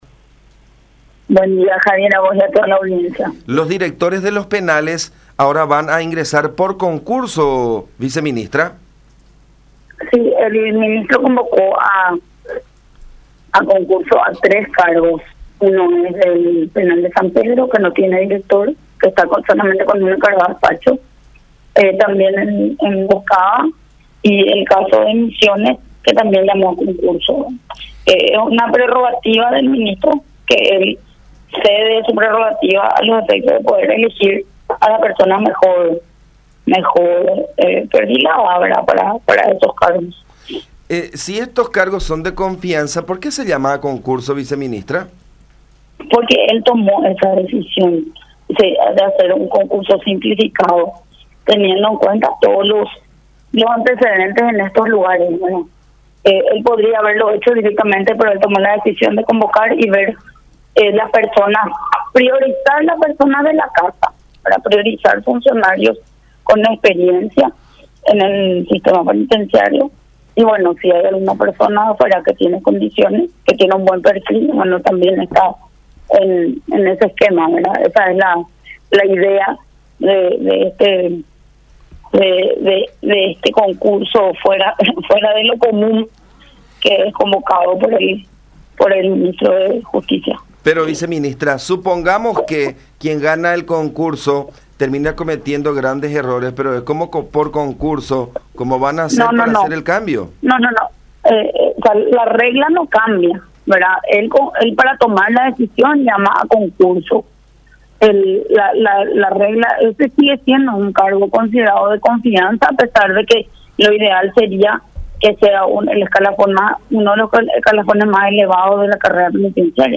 “Se busca contar con el mejor perfil para poder llenar estos cupos”, dijo Cecilia Pérez, Viceministra de Política Criminal, en comunicación con La Unión, en referencia específica a tres direcciones de penitenciarías: San Pedro, Emboscada y Misiones.